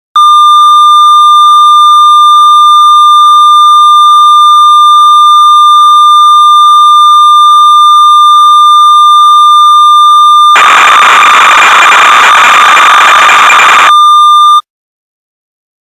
Project Information Spreadsheet – OB-X Project Information-V1.xlsx Initial Factory Patch Data – Oberheim OB-X Factory Patches YouTube.wav Factory Patch Data Re-Recorded – OB-X Factory Patch Data.wav Recordings of Factory Tones – Oberheim_OB-X_factory_mp3.zip
oberheim-ob-x-factory-patches-youtube.wav